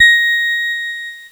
Cheese Note 28-A#4.wav